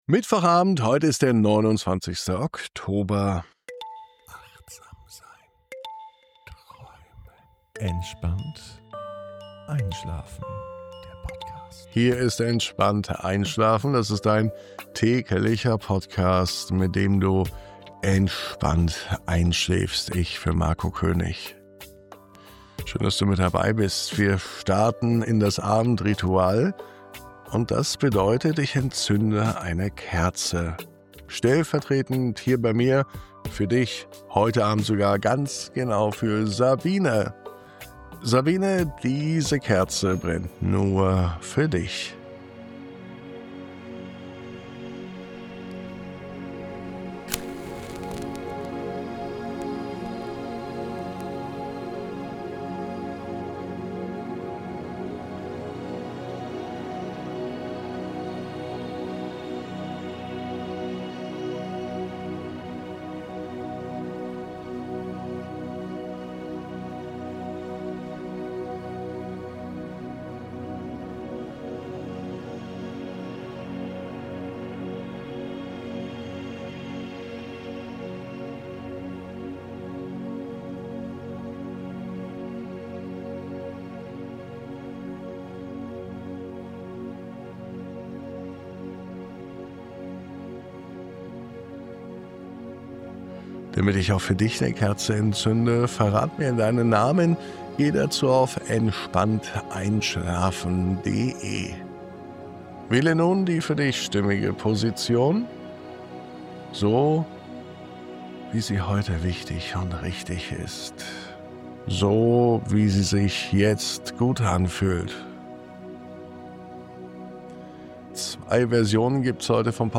Diese Traumreise führt dich an einen Ort, den du schon immer in dir getragen hast – ein Garten, der nur durch dein Herz lebendig wird. Dort findest du Raum zum Durchatmen, Erinnern und Loslassen.
Ein sanfter Weg zurück zu dir selbst, getragen von Wärme und innerer Ruhe.
1029_MUSIK.mp3